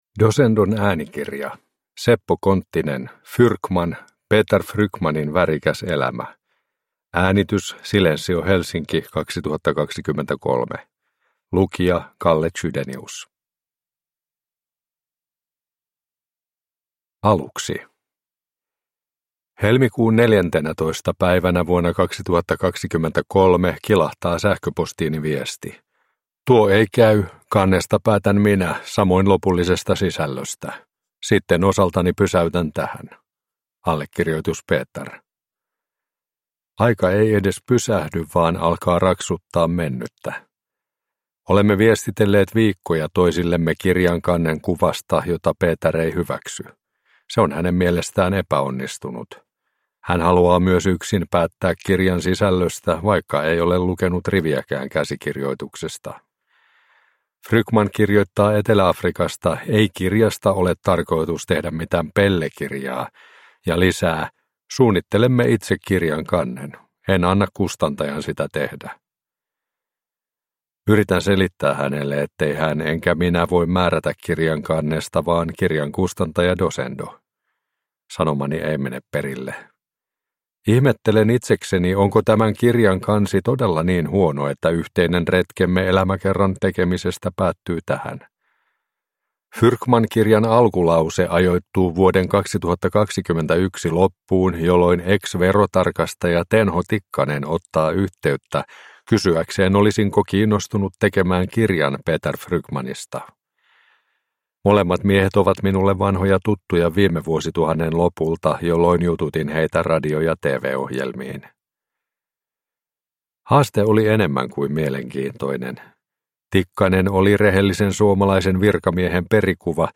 Fyrkman – Ljudbok – Laddas ner